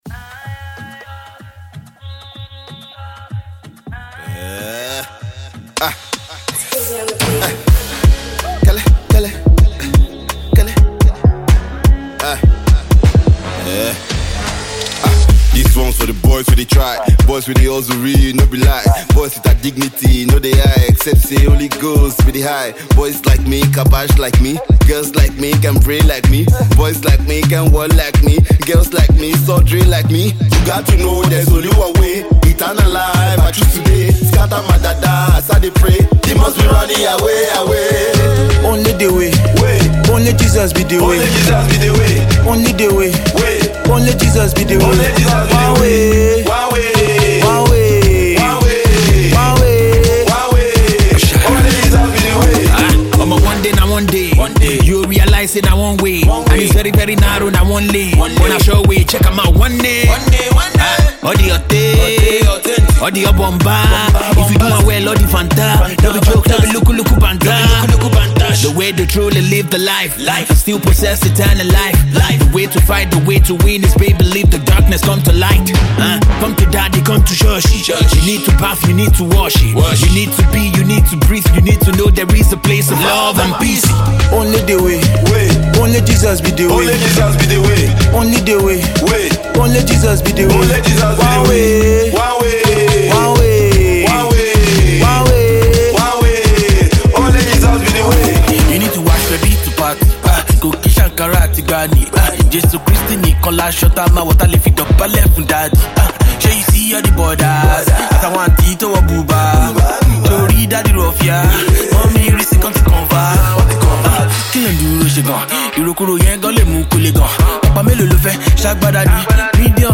Versatile gospel musician and prolific song writer